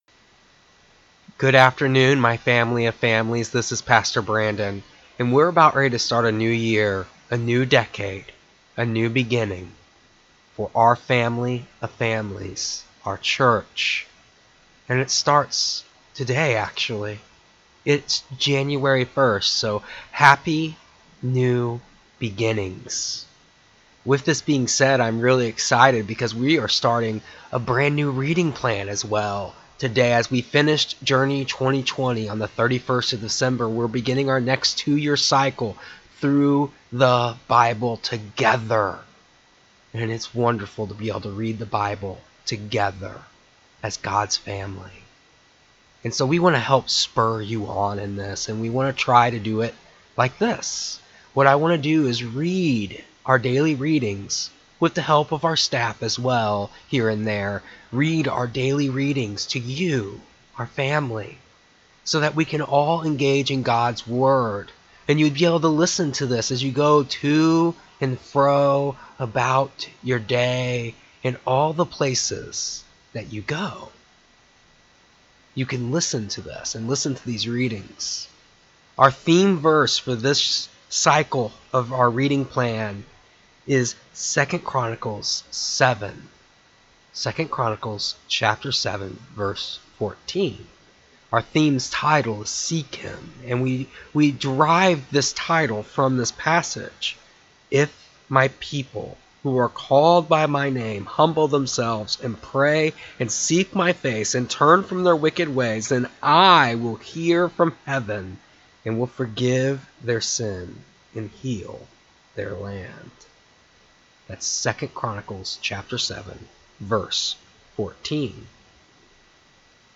and others will be coming together daily to read the readings from the daily reading plan